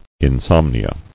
[in·som·ni·a]